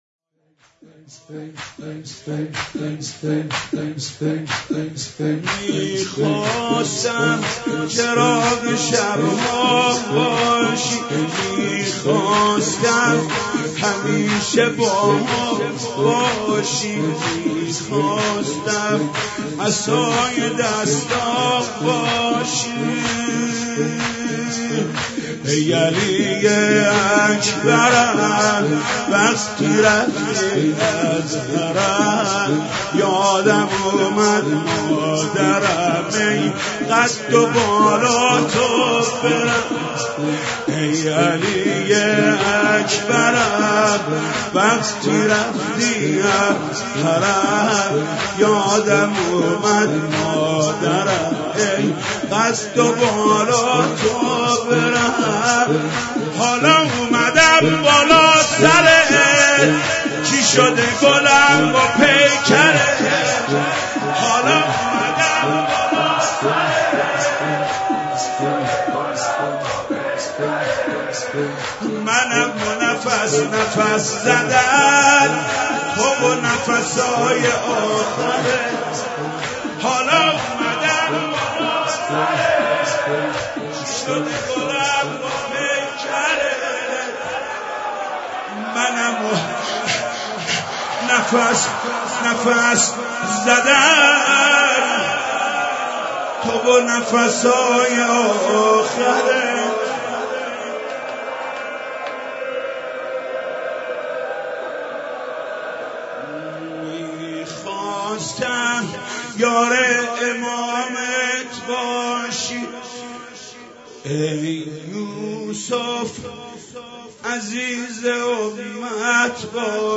شب هشتم
(زمینه – حضرت علی اکبر سلام الله علیه)